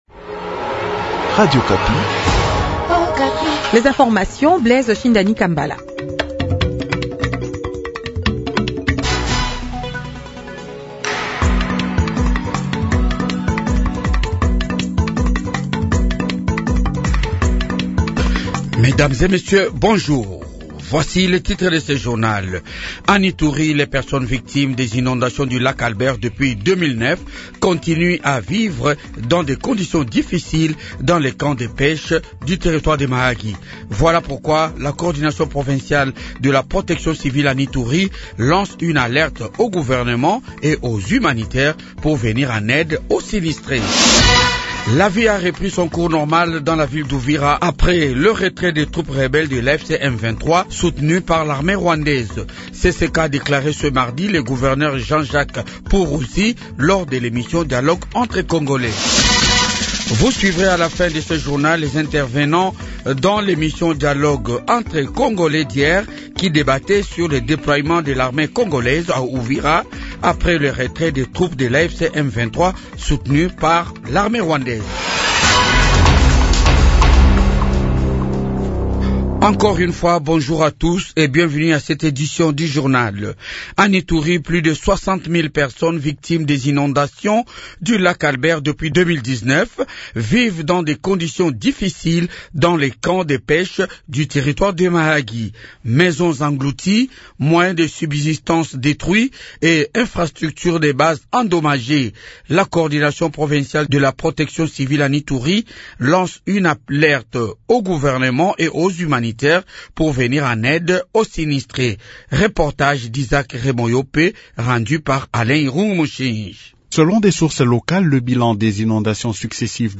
Journal du matin 8h